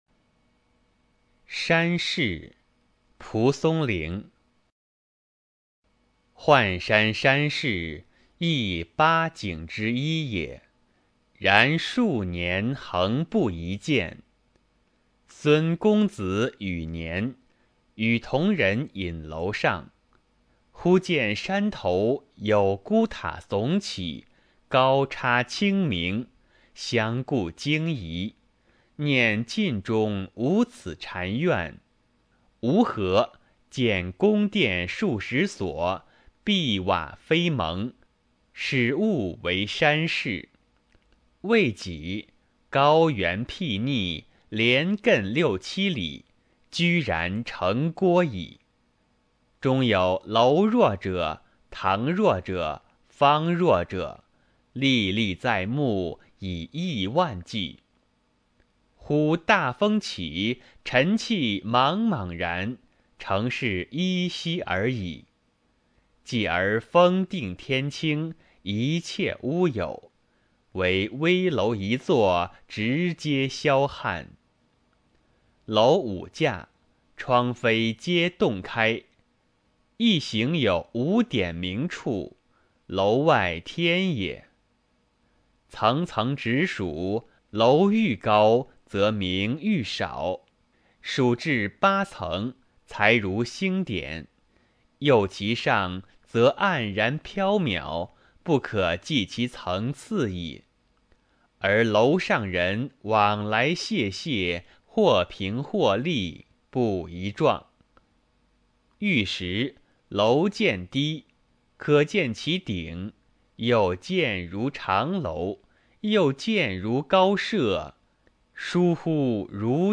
蒲松龄《山市》原文和译文（含朗读）　/ 蒲松龄